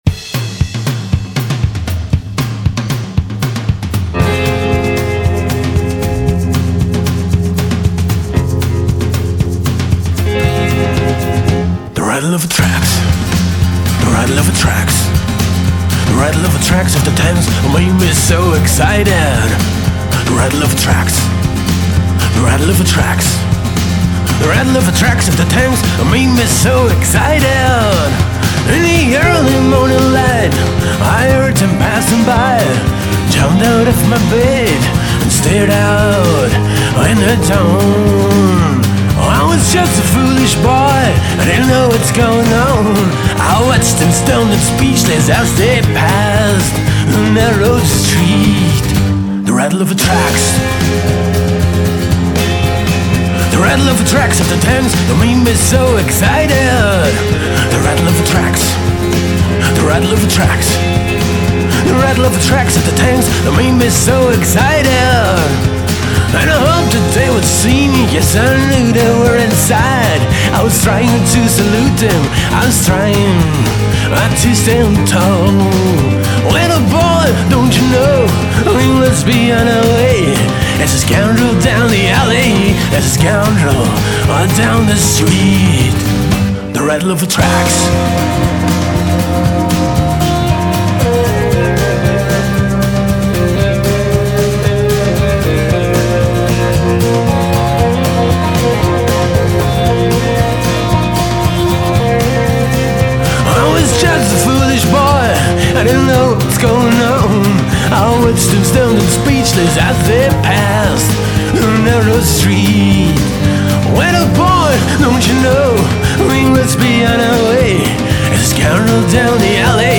drums
bass
electric guitars
vocals, acoustic guitar